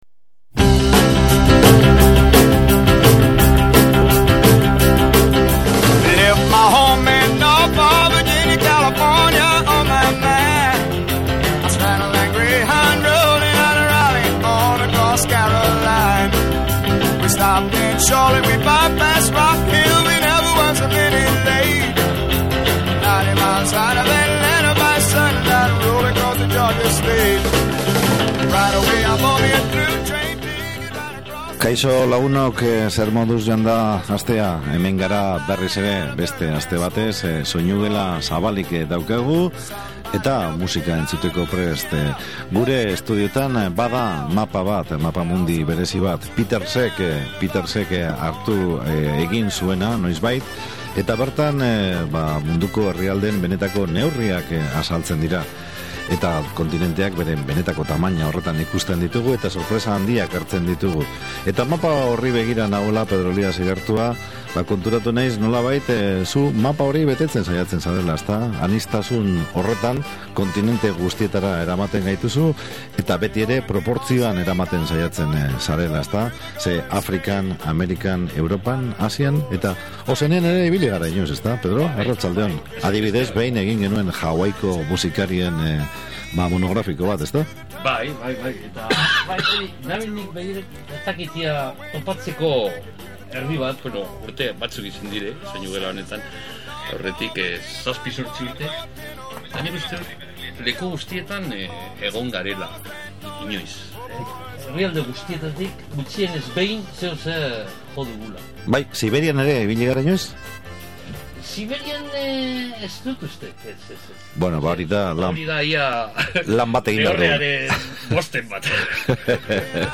Amerikako bluesa eta arabiar musikaren nahasketa
sustraidun rocka